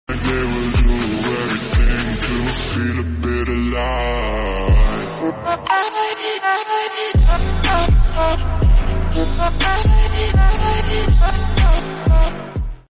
Shenyang J 31 fighter jet china sound effects free download